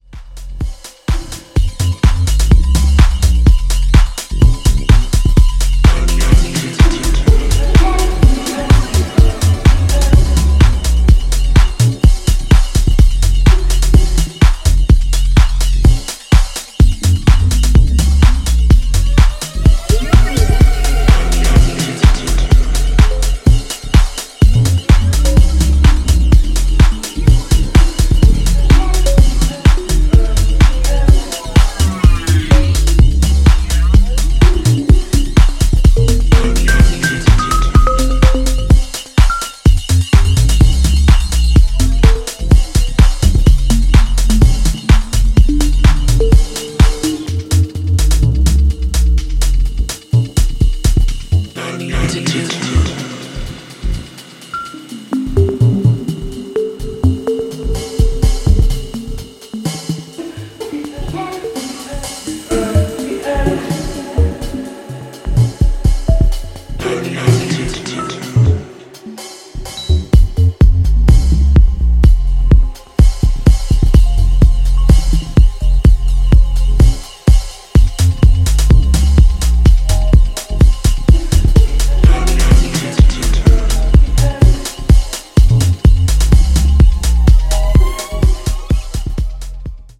不穏にうねるブリーピーなサブベースとスウィングしたハットの絡みが上々な